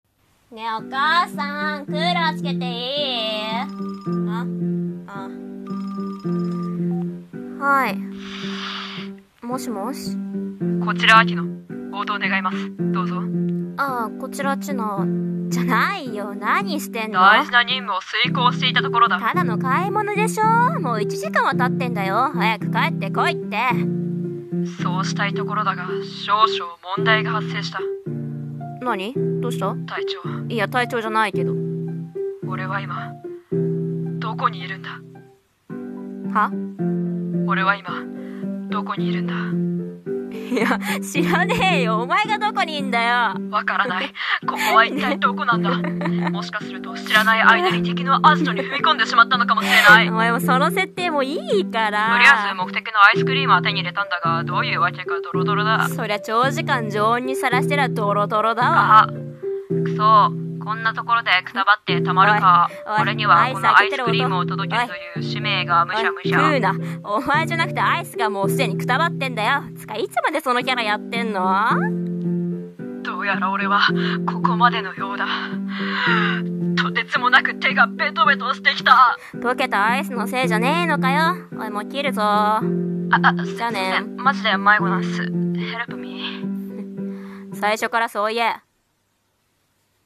【ギャグ声劇台本】